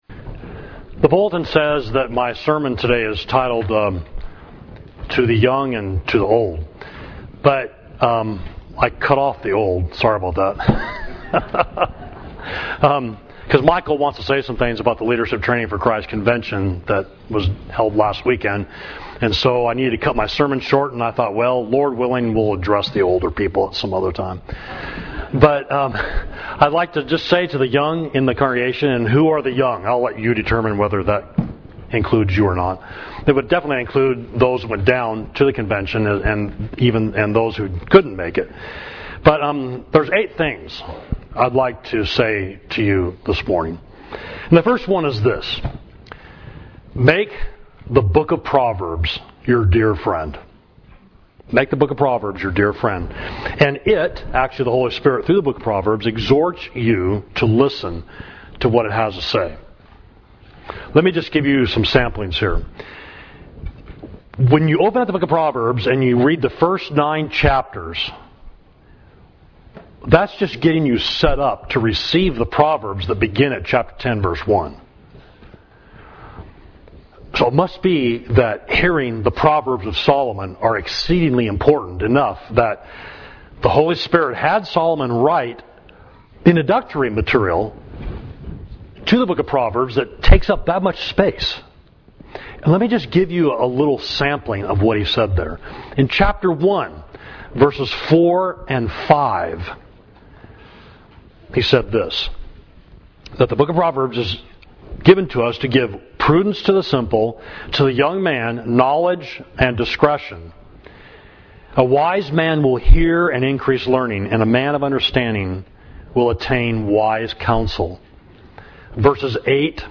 Sermon: To the Younger People